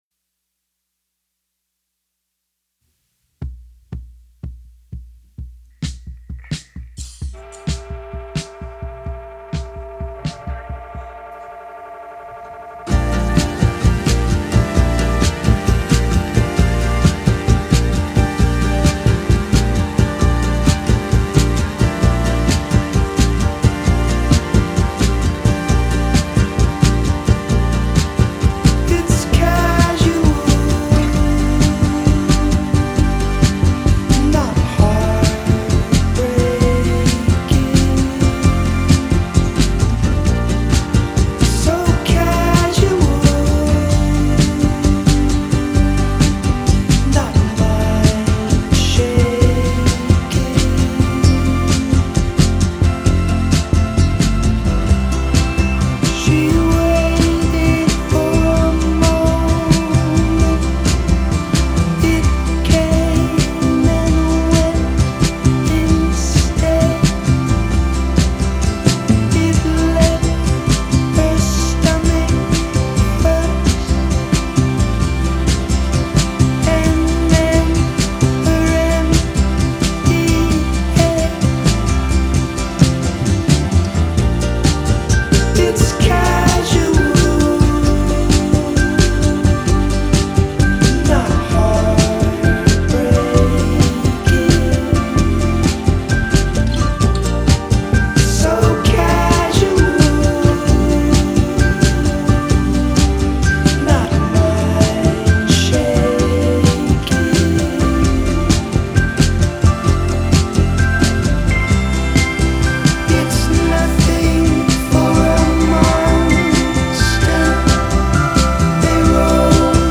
Ooh, dreamy. Perfect post-rave chill music.